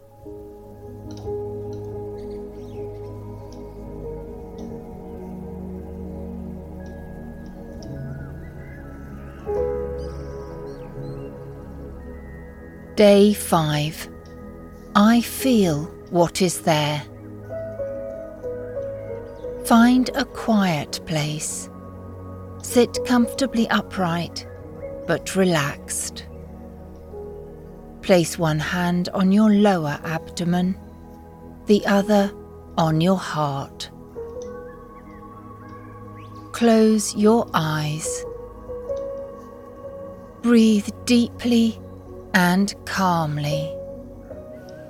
Female
I offer an RP British voice with excellent diction and an expressive, nuanced delivery.
My voice is warm, clear, and naturally engaging.
Narration
Mindfulness Meditation
1119MindfulnessMusic.mp3